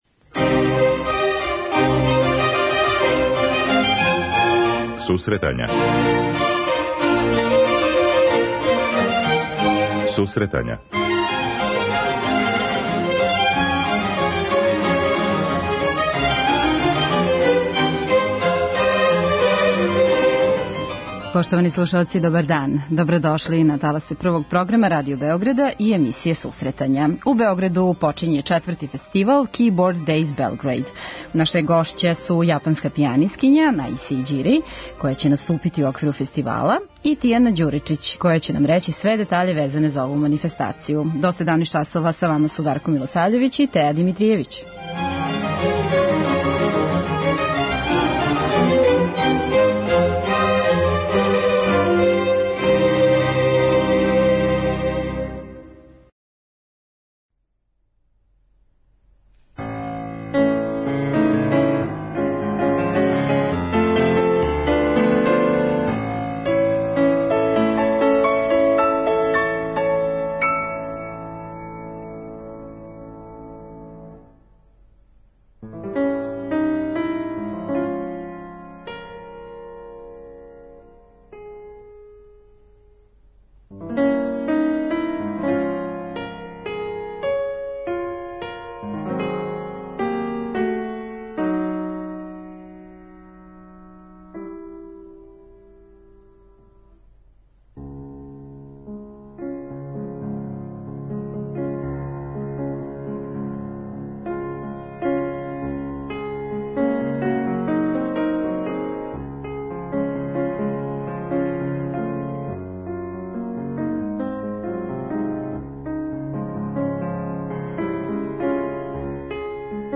преузми : 10.74 MB Сусретања Autor: Музичка редакција Емисија за оне који воле уметничку музику.